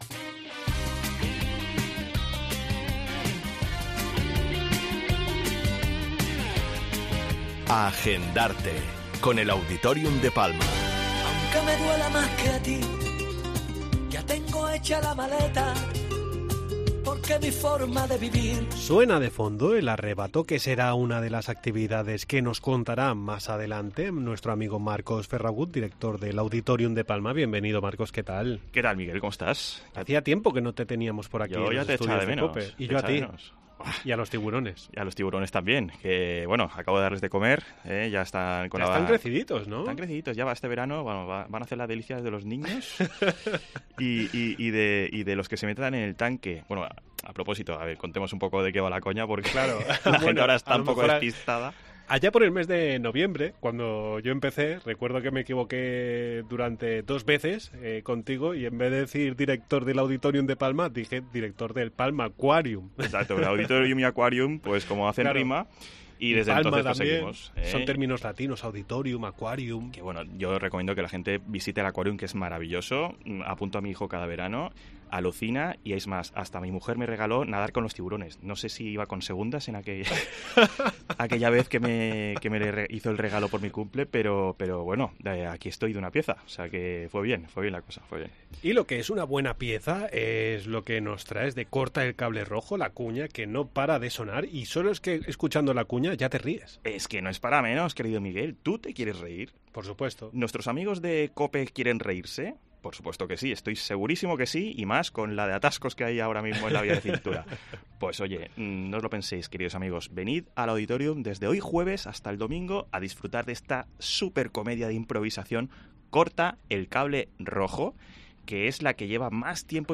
Entrevista en 'La Mañana en COPE Más Mallorca', jueves 11 de mayo de 2023.